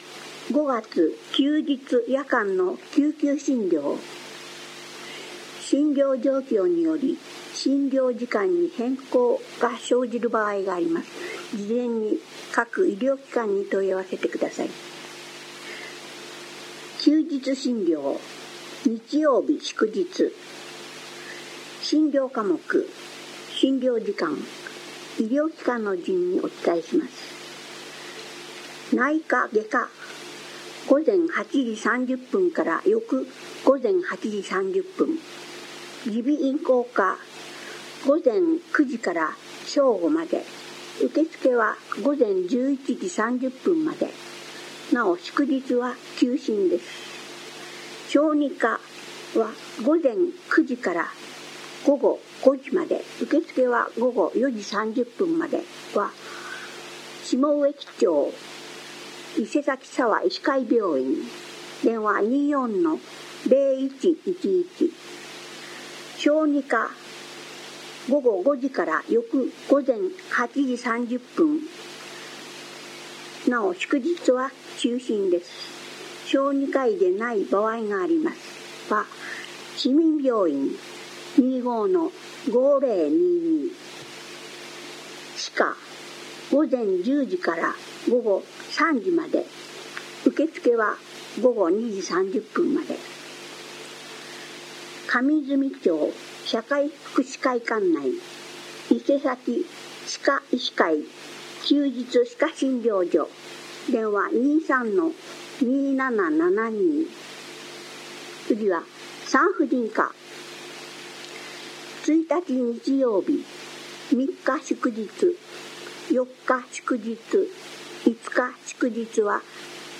声の広報は目の不自由な人などのために、「広報いせさき」を読み上げたものです。
朗読